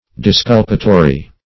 Disculpatory \Dis*cul"pa*to*ry\